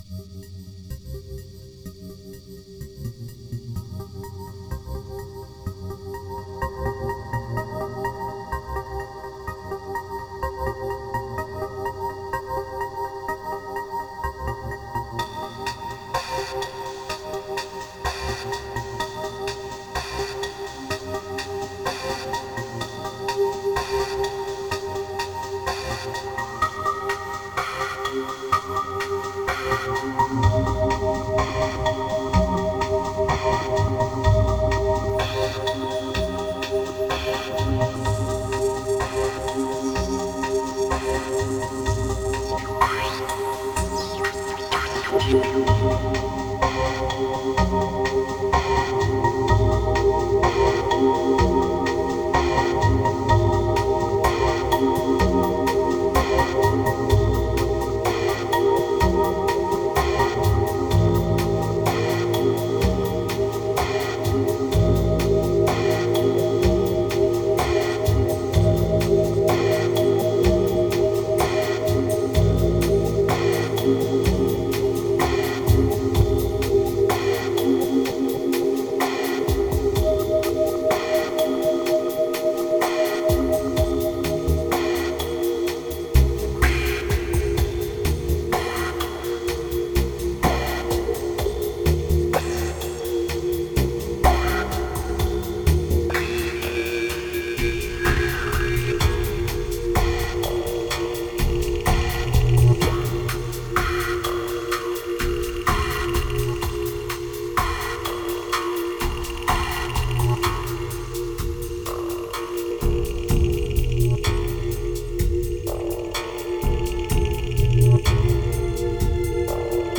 2156📈 - 89%🤔 - 63BPM🔊 - 2012-08-23📅 - 352🌟